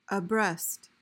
PRONUNCIATION: (uh-BREST) MEANING: adverb: 1.